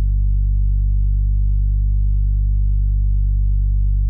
Bass loop Free sound effects and audio clips
• HardGroove - Techno Pitch Bend Bass (10) - G - 107.wav